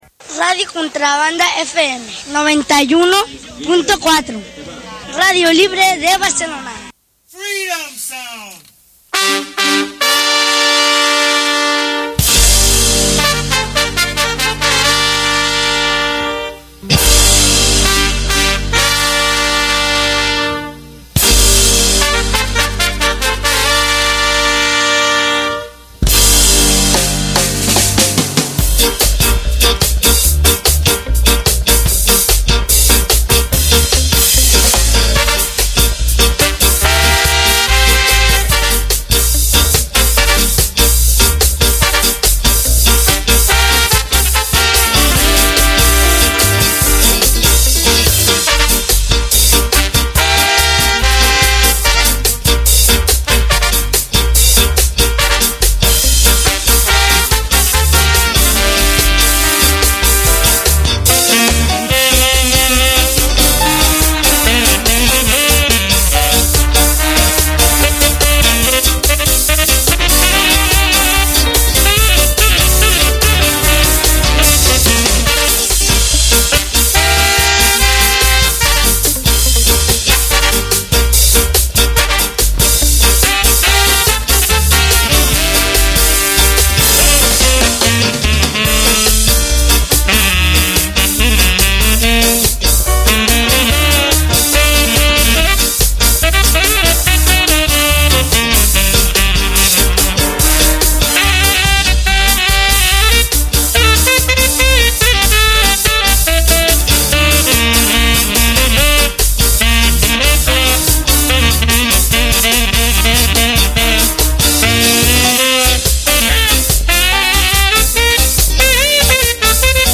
Aquesta setmana us hem preparat un programa dedicat a la música ska italiana de finals dels 80/inici dels 90’s. Esperem que us agradi!